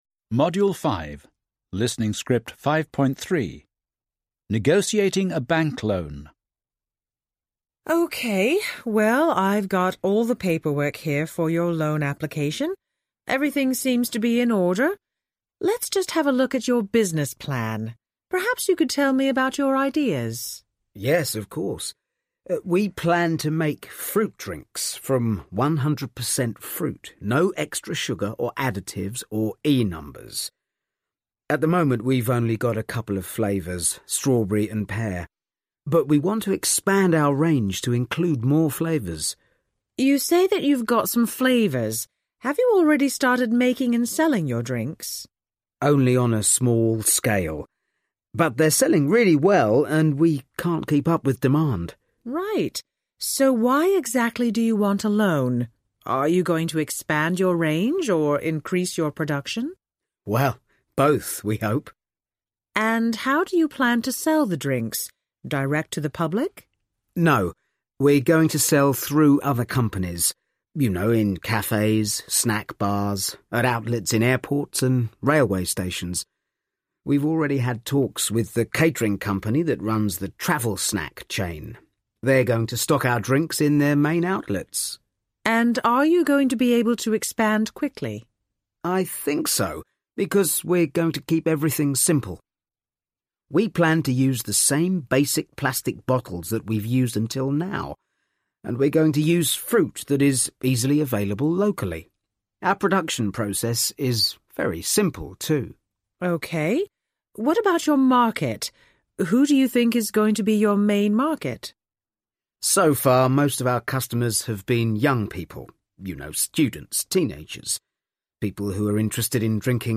1 Where does the woman work?
4 What is the man’s business plan?